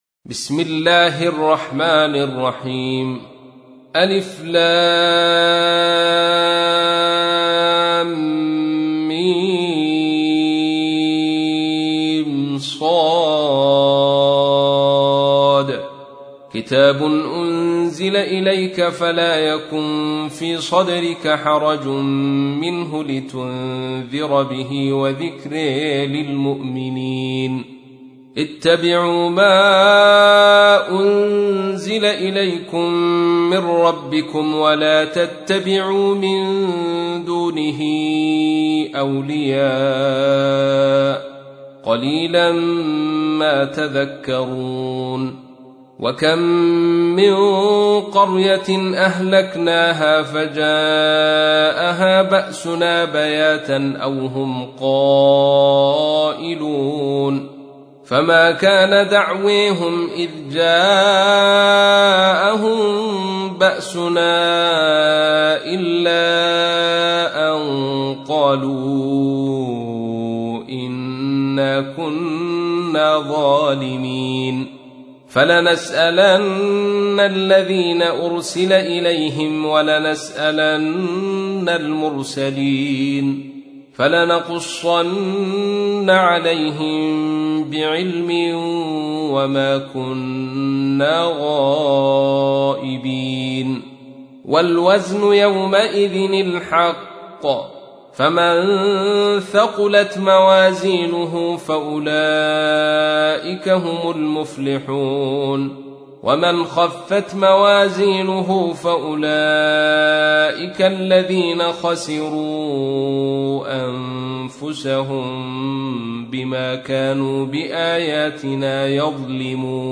تحميل : 7. سورة الأعراف / القارئ عبد الرشيد صوفي / القرآن الكريم / موقع يا حسين